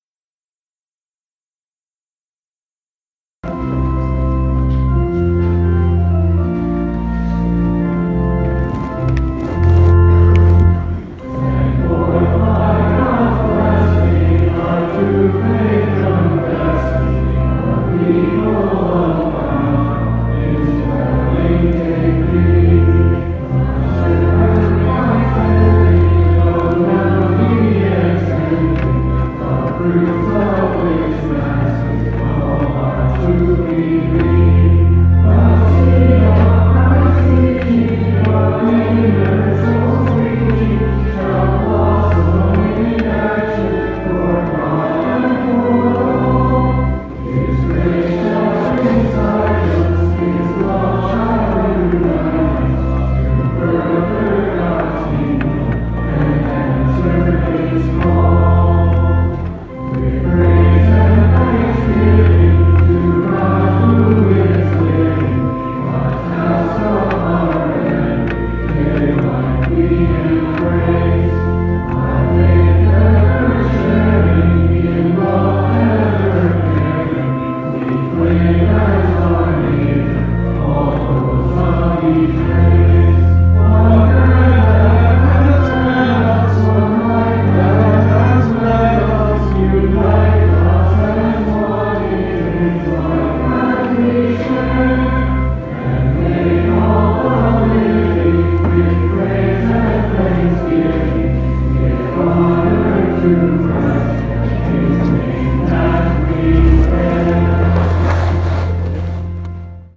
Sent Forth by God's Blessing - Choir with Organ - 2/10/2019